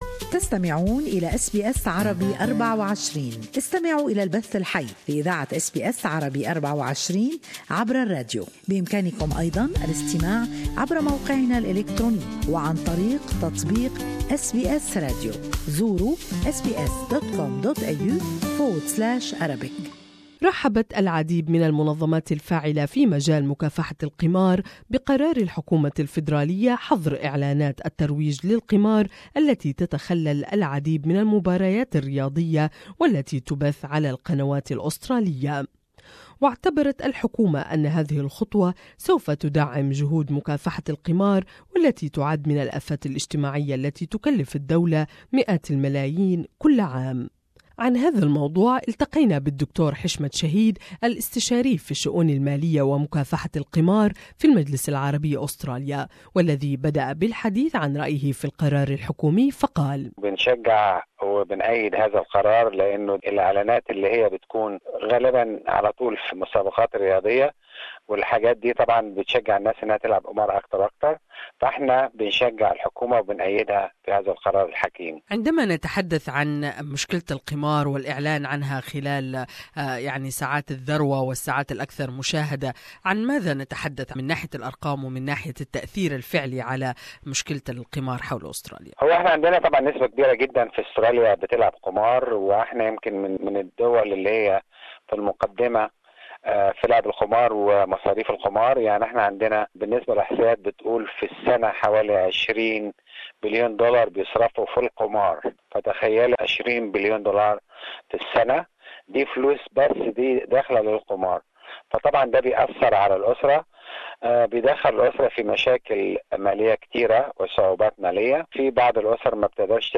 Australia's major sporting codes have criticised a media reforms package announced by the federal government, which would see gambling ads banned from live sport broadcasts before 8:30pm. The reforms also includes changes to broadcast licensing fees - a move which has been welcomed by the media industry. More in this interview with gambling and financial consultant